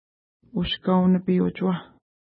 ID: 61 Longitude: -58.6492 Latitude: 52.7766 Pronunciation: u:ʃka:w-nəpi:-utʃuwa: Translation: New Antlers Lake Mountains Feature: mountains Explanation: Named in reference to nearby lake Ushkau-nipi (no 60).